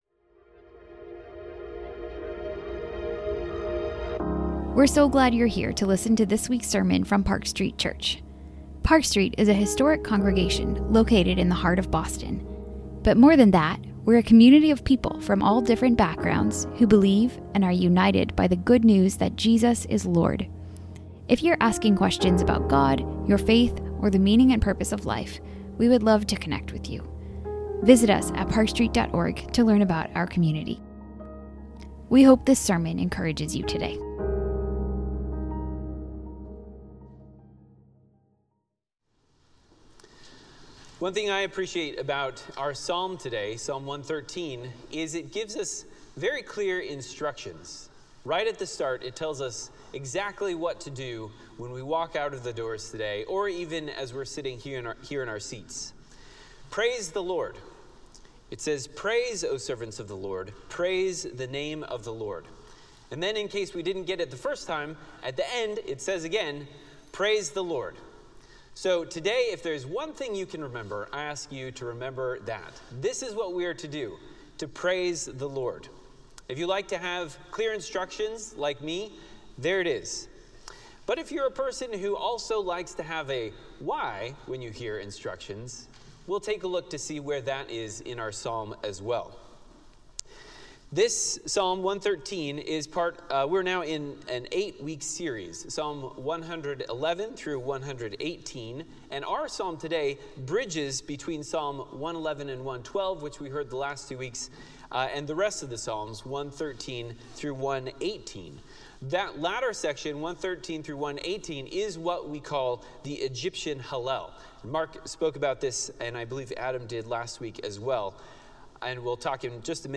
Sermons | Park Street Church